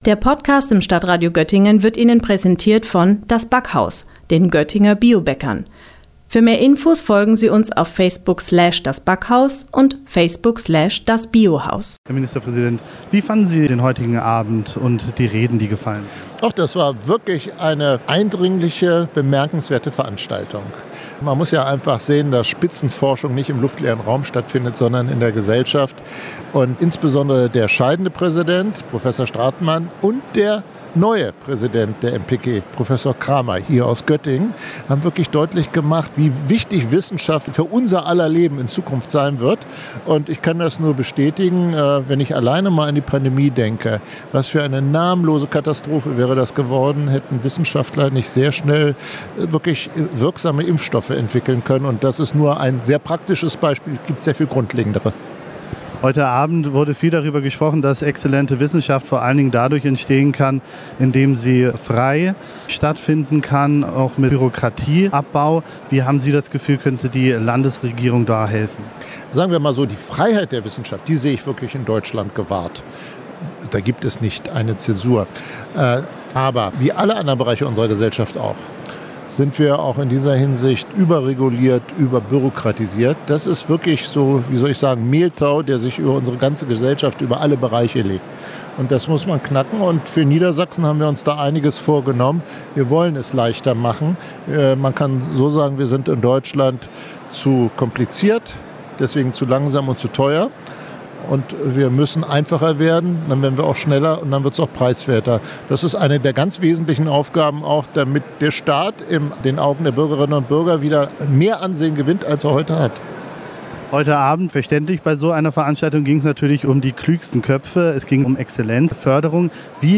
Beiträge > Ministerpräsident Stephan Weil im Interview zu den Themen Exzellenzförderung und Bildungspolitik - StadtRadio Göttingen